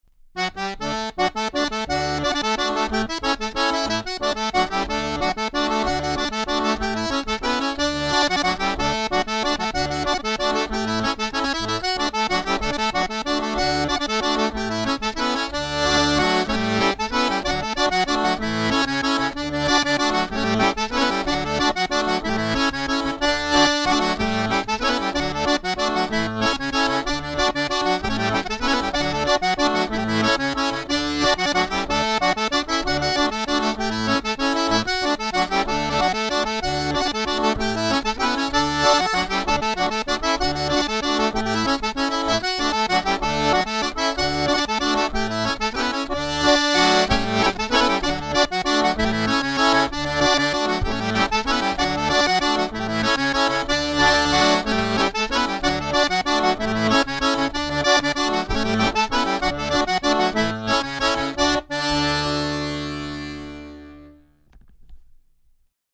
Accordéon "Emeraude"
Modèle d’accordéon « 1 rangée 2 basses » réalisé en noyer massif marqueté et équipé d’anches BlueStar de Voci Armoniche.
• 11 touches à 4 voix